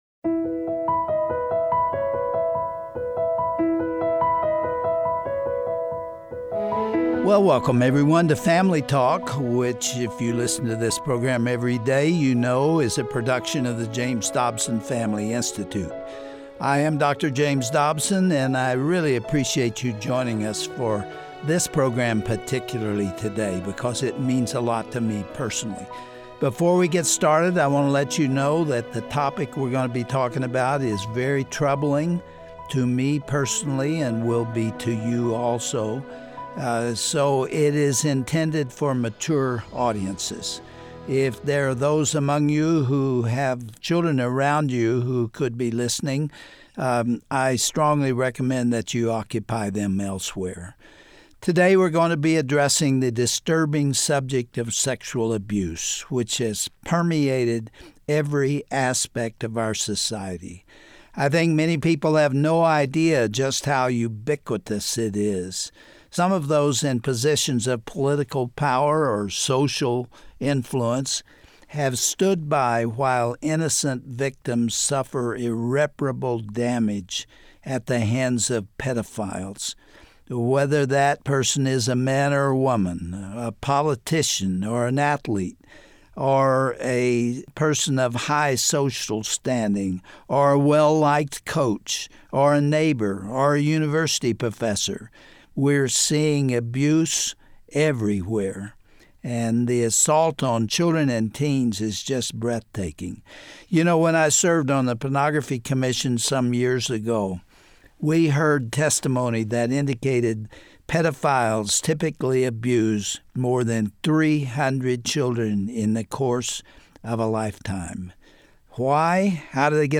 Did you know sexual abuse has longer-lasting mental health effects than any other crime, with PTSD rates second only to combat veterans? On today’s edition of Family Talk, Dr. James Dobson hosts a powerful conversation with survivor Rachael Denhollander about the Larry Nassar scandal that rocked USA Gymnastics.